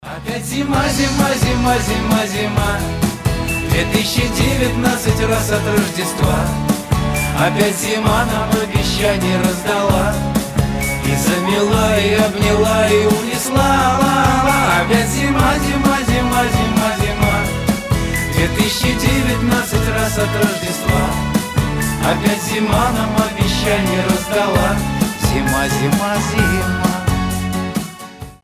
• Качество: 320, Stereo
гитара
душевные
спокойные
поп-рок